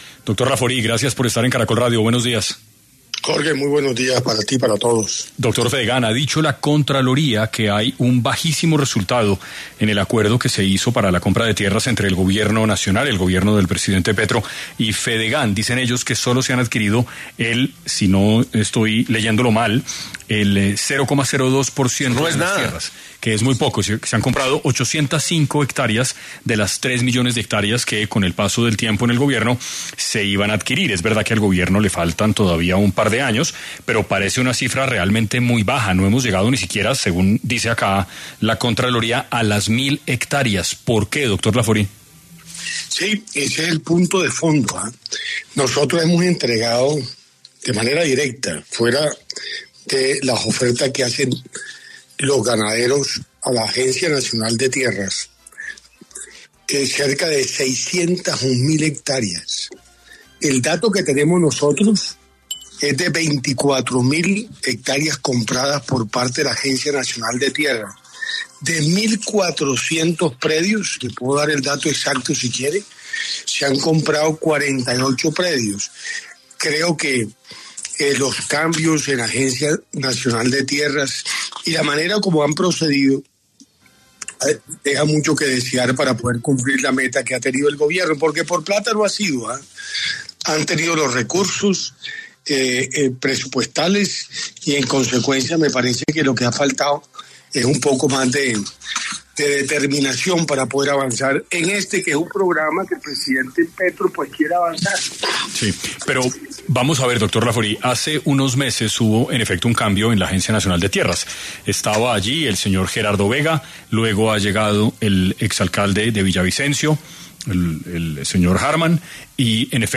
En 6AM de Caracol Radio estuvo José Félix Lafaurie, presidente de Fedegán, quien habló sobre por qué no han sido buenos los resultados del acuerdo entre el Gobierno y Fedegán para la compra de tierras en Colombia.